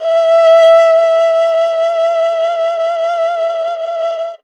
52-bi18-erhu-p-e4.wav